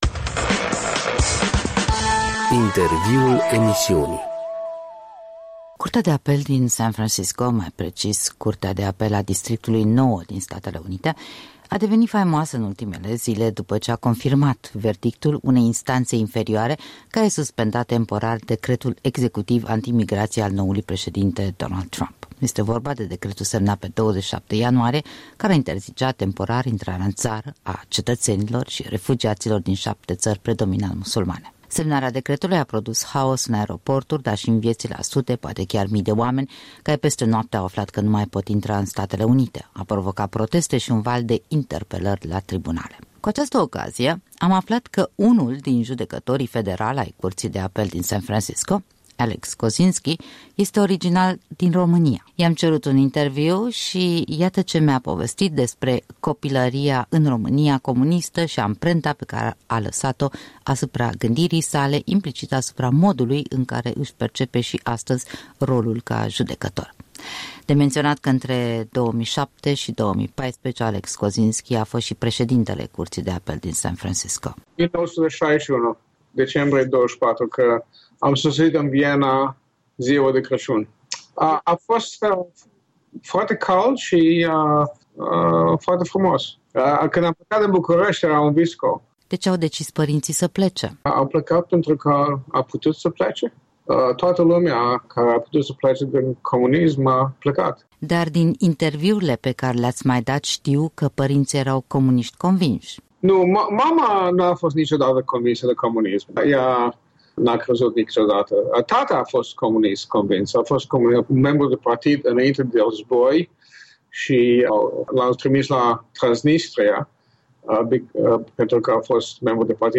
Un interviu cu unul din judecătorii federali ai Curții de Apel din San Francisco.